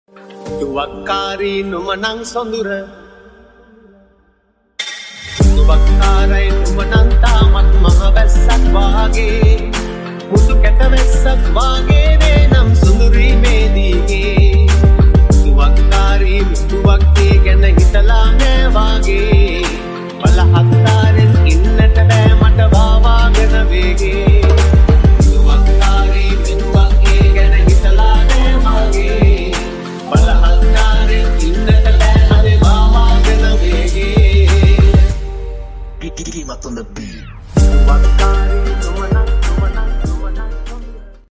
Sri Lankan Airlines Landing at sound effects free download
Sri Lankan Airlines Landing at Katunayaka Airport Sri Lanka